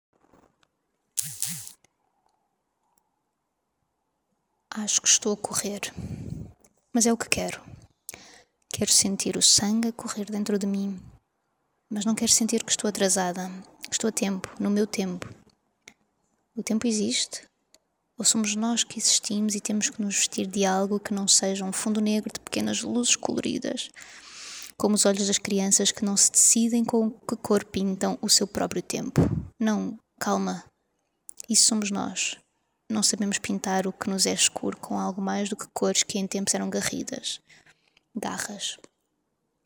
Title Escrita automática Description Reading of an automatic writing. Can be used has a score to improvise or to compose.